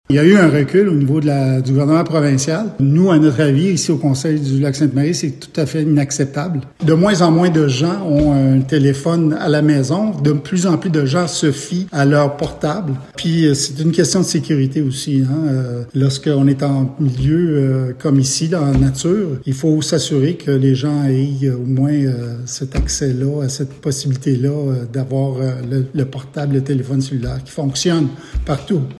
Le maire de Lac-Sainte-Marie, Marc Beaudoin, en dit davantage :